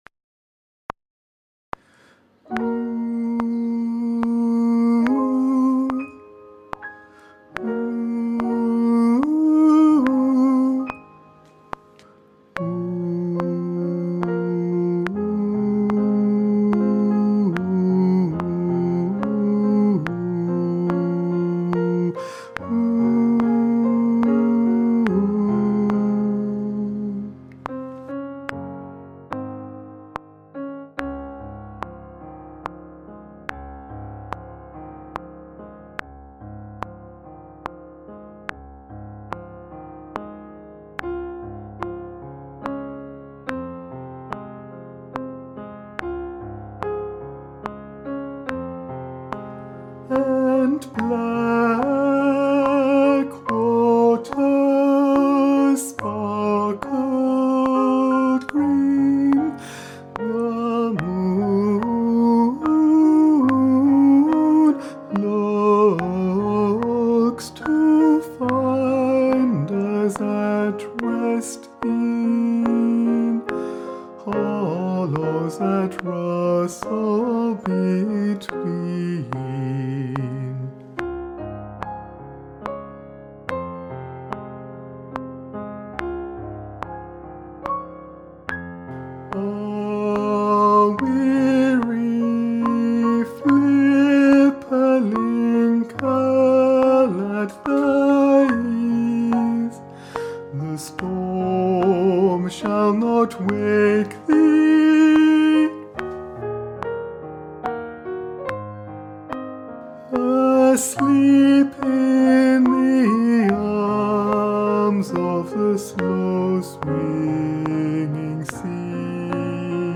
- Œuvre pour chœur à 5 voix mixtes (SATBB) + piano
Tenor Chante